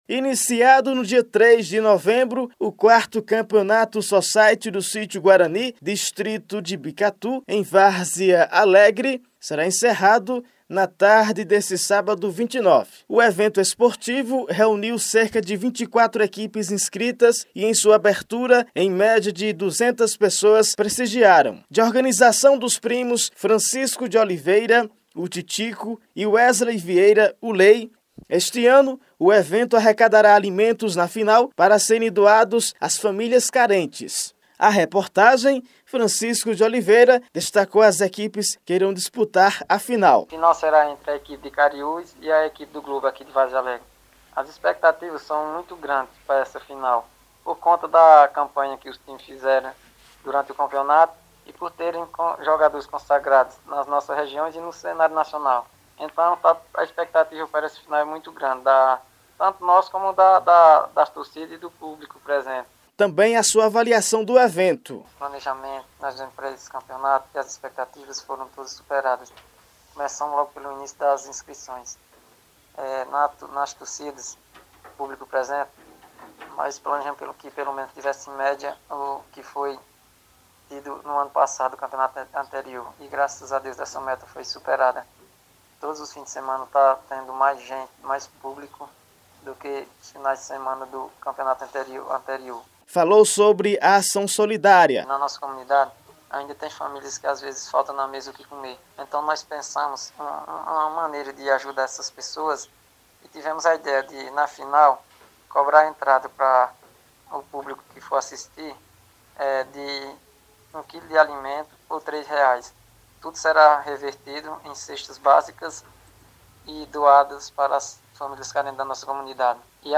Confira a reportagem de áudio, exibida pelo Grande Jornal da Cultura FM 96.3.